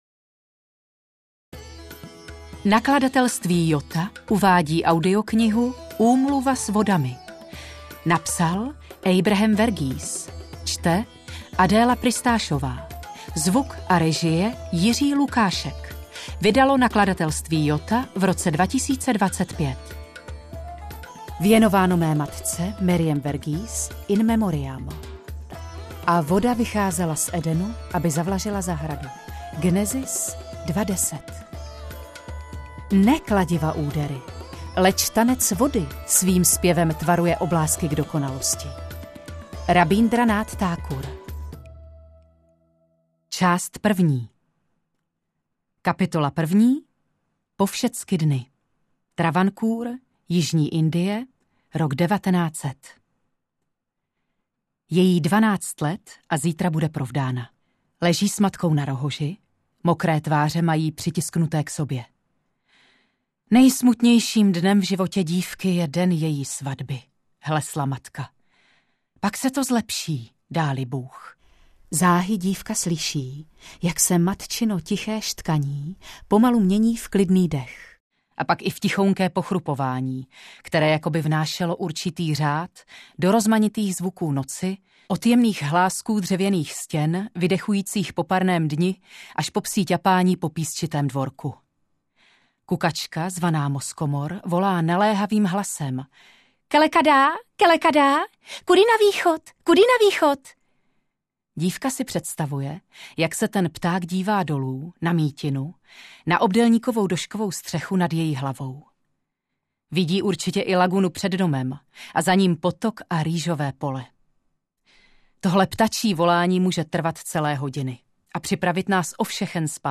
AudioKniha ke stažení, 91 x mp3, délka 30 hod., velikost 1643,4 MB, česky